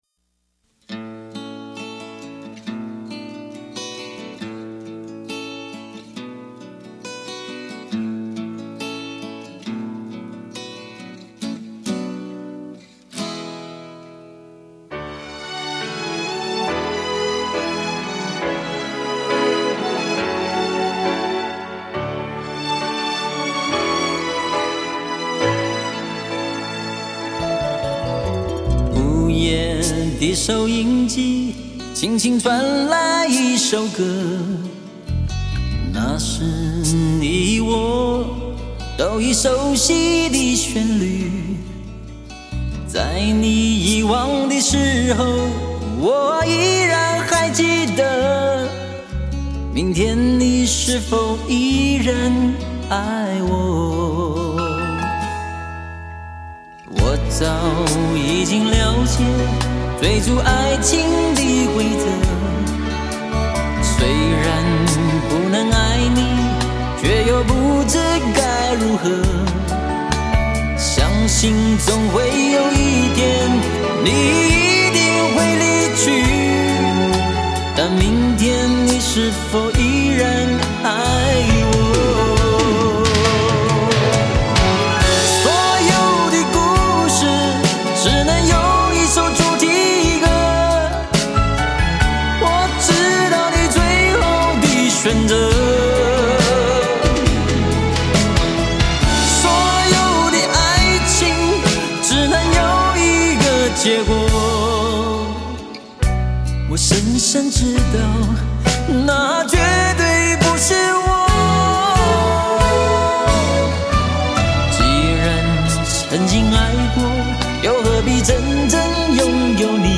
无损音质原人原唱，经典！